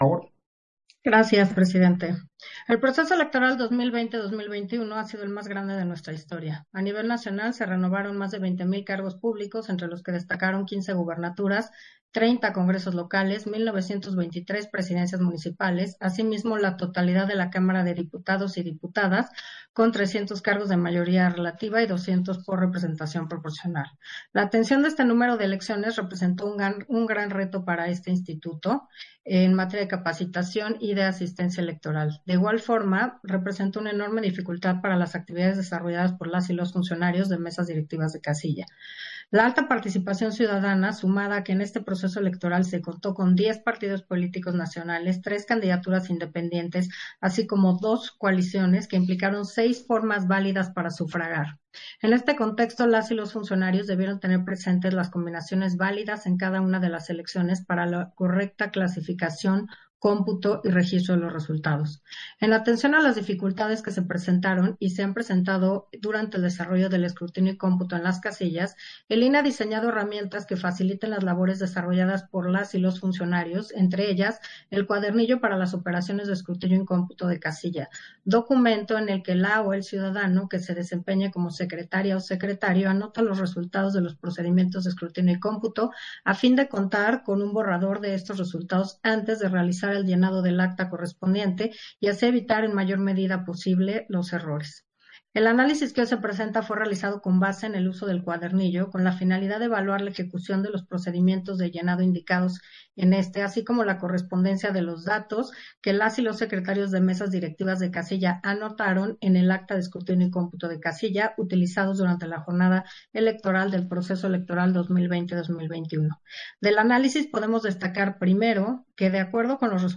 Intervención de Carla Humphrey, en el punto 7 de la Sesión Extraordinaria, relativo a la presentación del análisis muestral del llenado de cuadernillos de operación de escrutinio y cómputo de casilla de la elección para diputaciones federales 2021